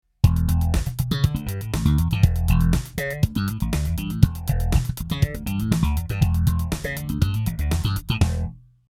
エフェクター・サウンド・サンプル
MAXON PH-350 (phaser)
♪MP3←6段 (ATELIER Z)